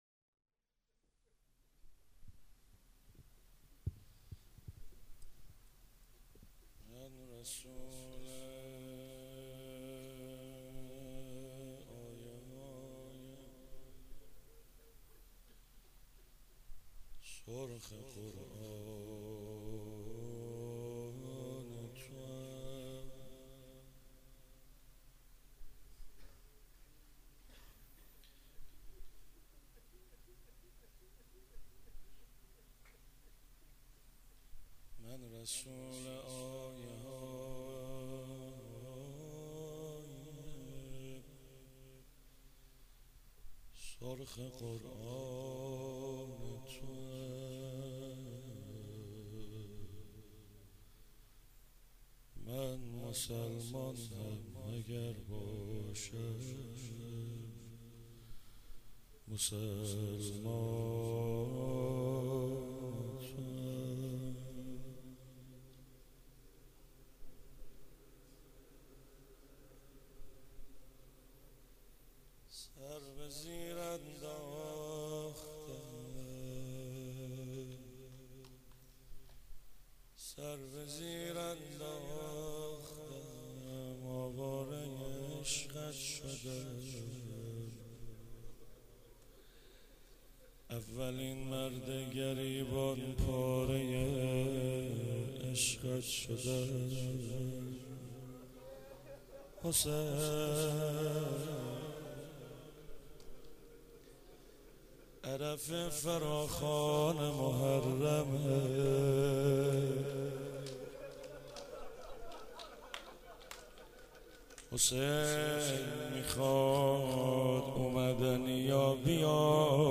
مراسم هفتگی 9 شهریور 96
حسینیه حضرت زینب (سلام الله علیها)
روضه
شور
شعرخوانی